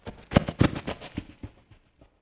running fast3.wav